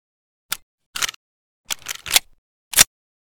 aps_reload_empty.ogg